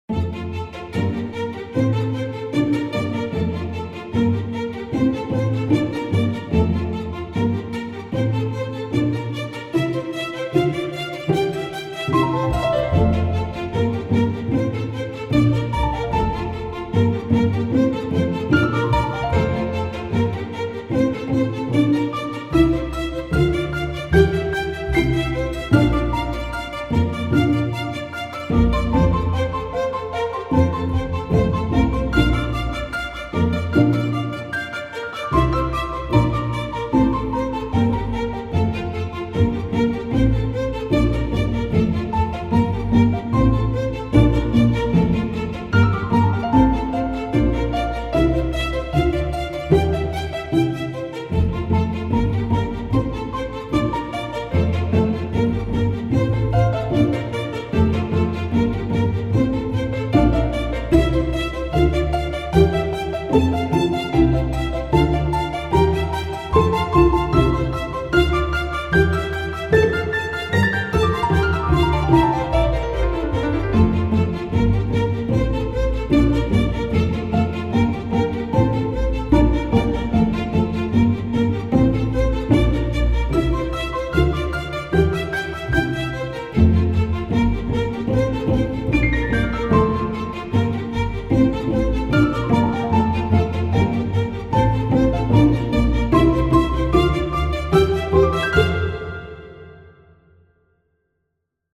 Orchestral, Chamber and Cinematic